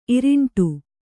♪ iriṇṭu